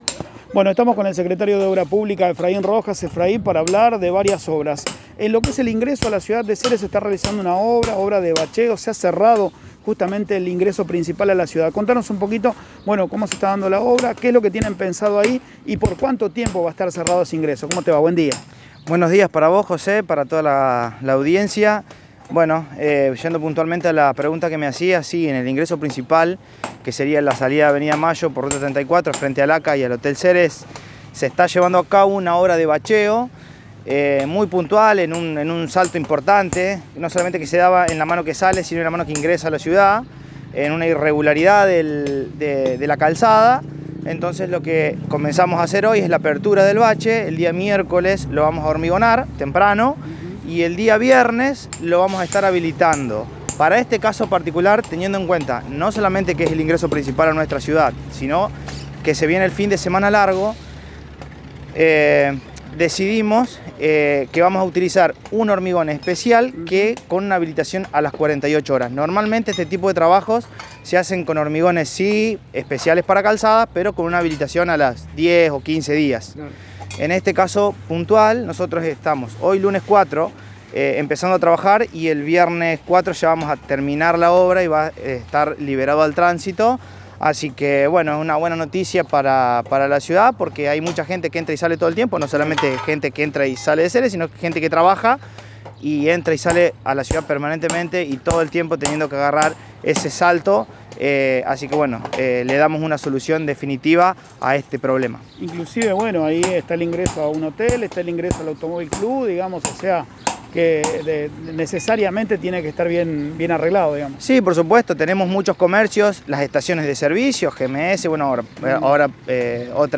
Según explicó el Secretario de Obra Pública Efraín Rojas  en FM CERES 98.7 Mhz. la intención es mejorar ese sector del pavimento que se había descalzado.